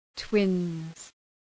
{twınz}